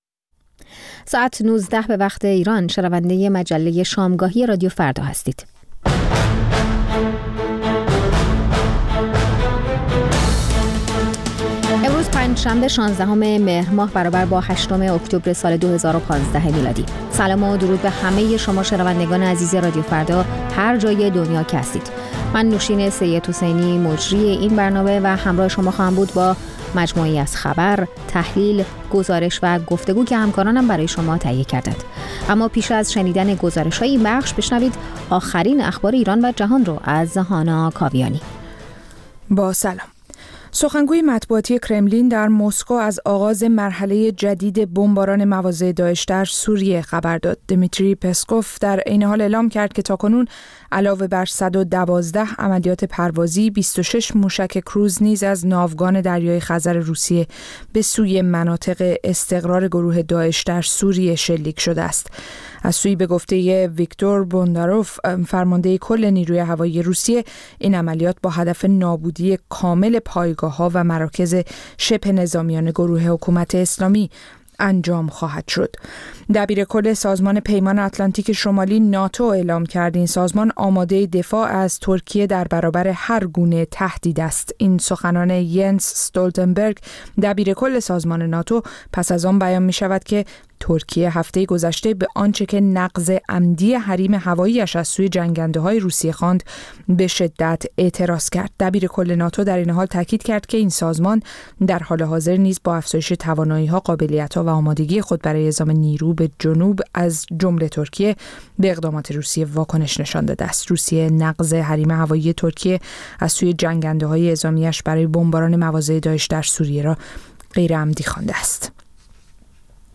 در نیم ساعت اول مجله شامگاهی رادیو فردا، آخرین خبرها و تازه‌ترین گزارش‌های تهیه‌کنندگان رادیو فردا پخش خواهد شد. در نیم ساعت دوم شنونده یکی از مجله‌های هفتگی رادیو فردا خواهید بود.